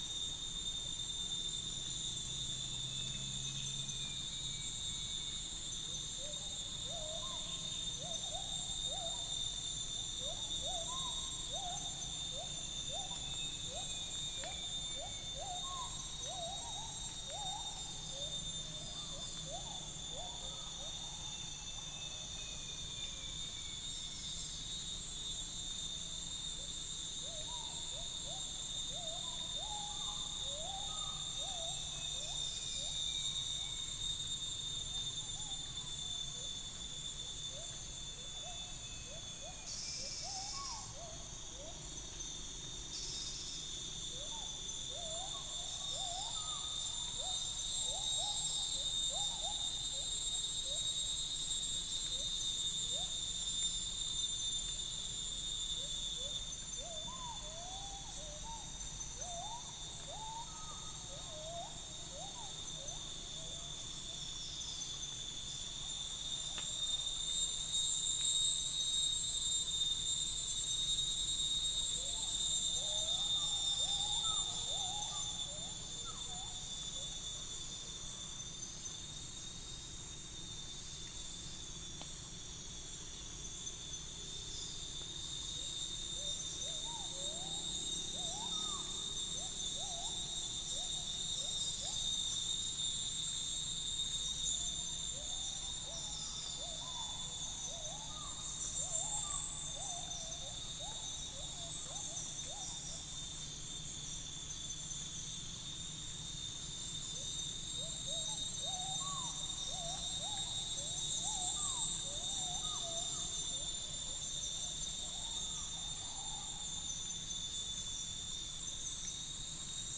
Upland plots dry season 2013
Spilopelia chinensis
Macronus ptilosus
Centropus bengalensis
Malacopteron magnirostre
Orthotomus atrogularis
Dicaeum trigonostigma